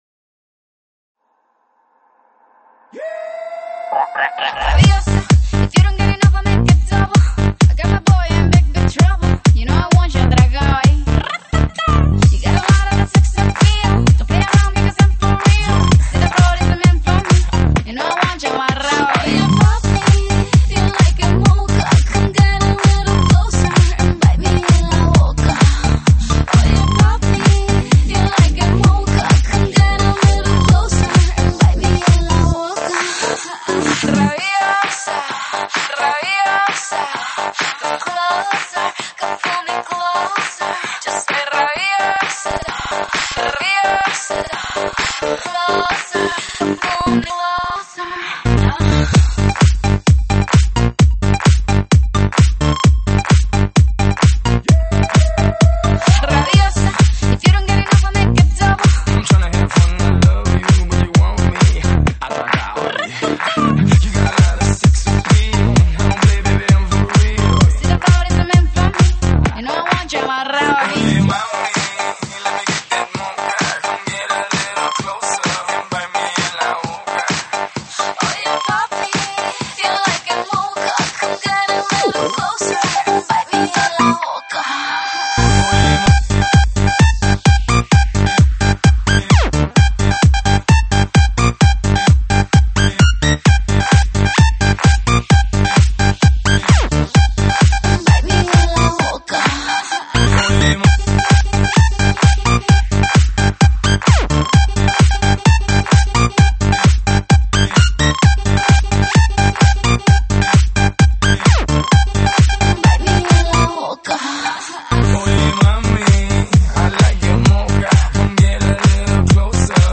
音质： 320 Kbps